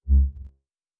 Lightsaber 1_2.wav